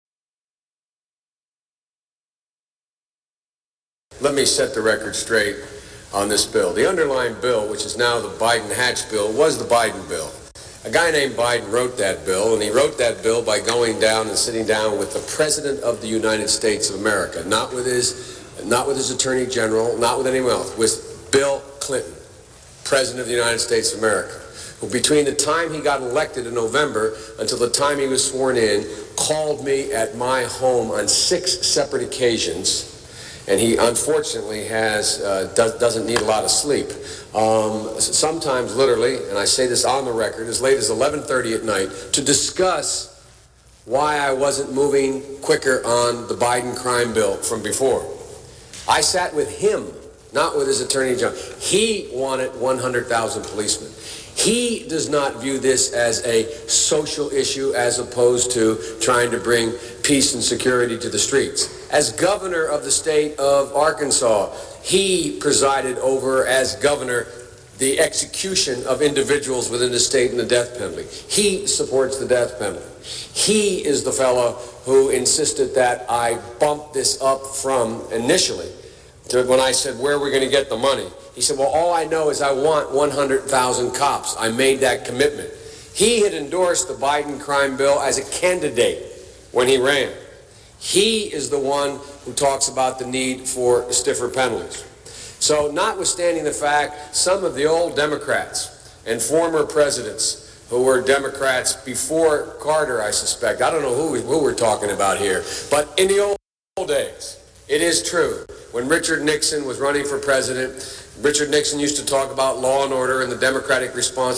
JOE BIDEN in his own words, Please Listen!!!! Lesser of two evils?